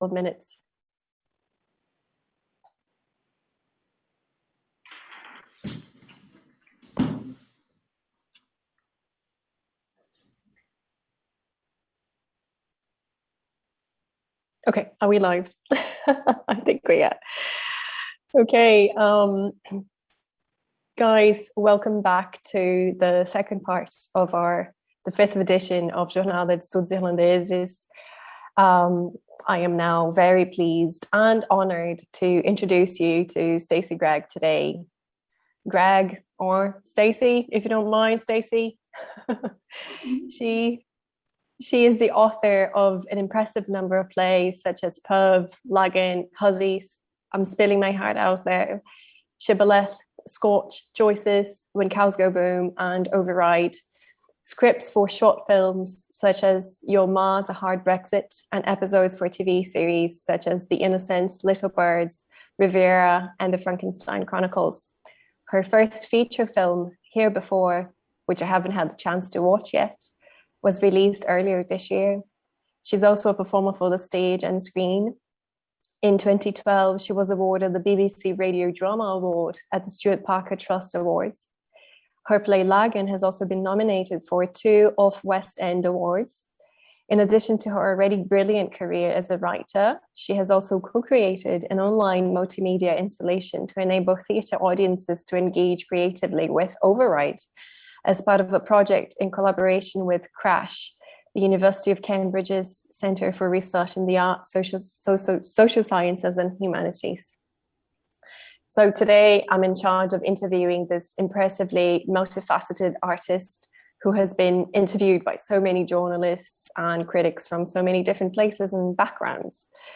Category Interview